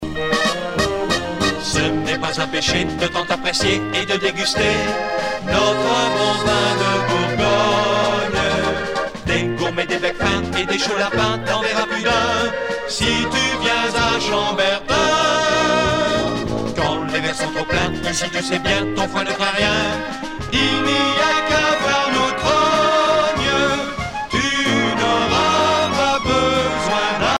valse musette
Pièce musicale éditée